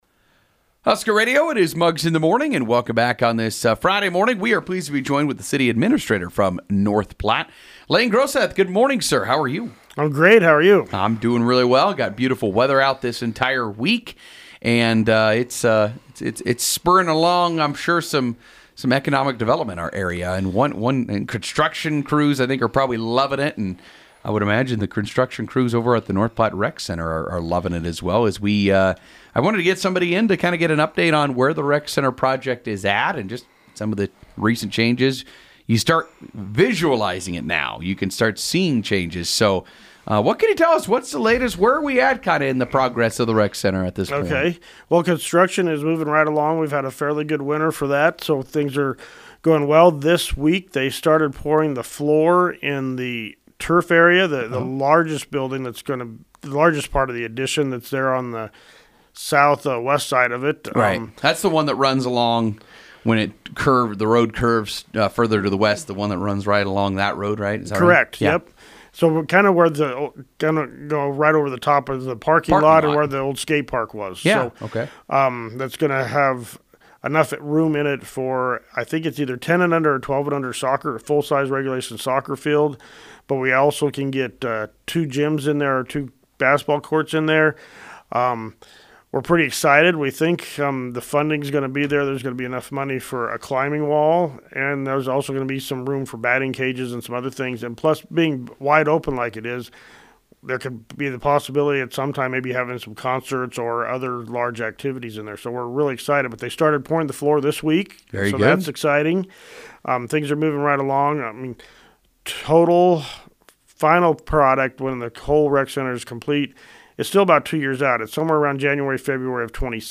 North Platte’s City Administrator Layne Groseth was in studio to give the listeners an update on the North Platte Rec Center construction project on Friday.